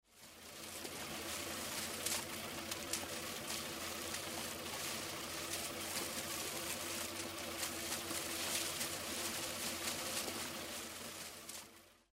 Movimiento de unas hélices pequeñas
Hélices de motores de aviación
Sonidos: Transportes